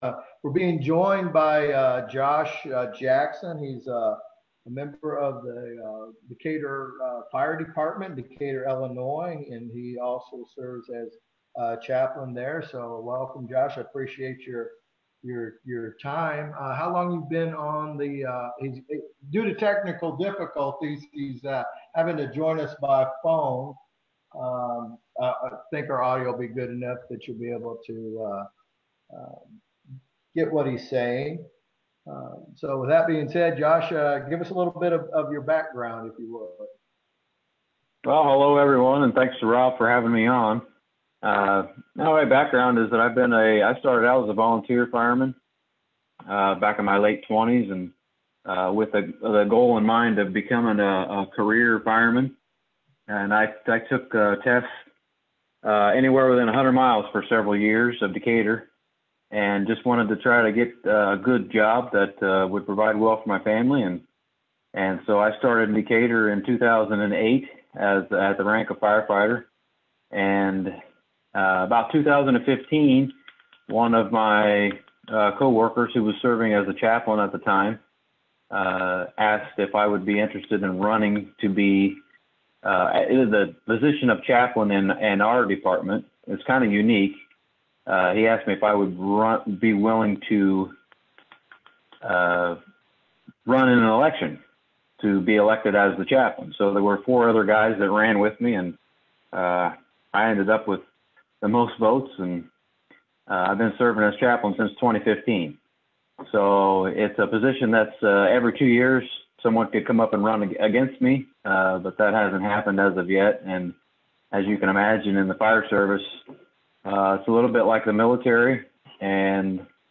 Interviews with Chaplains